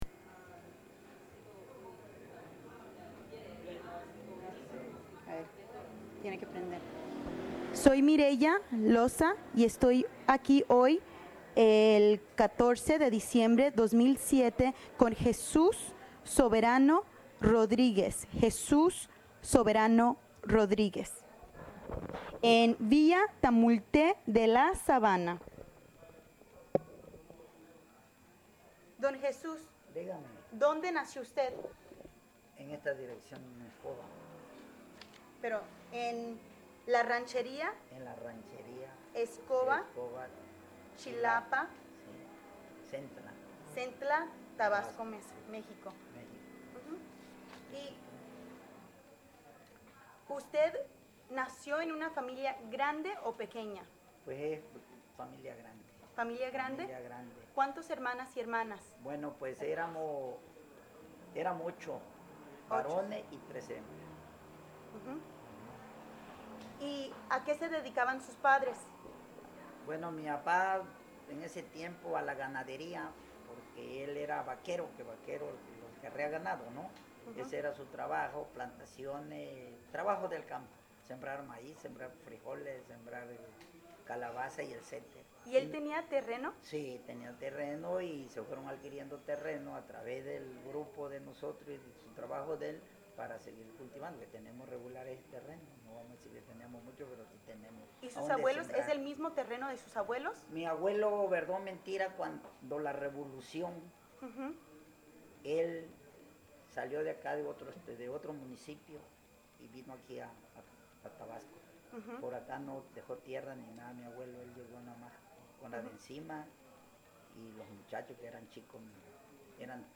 Location Villa Tamulte de la Sabana, Tabasco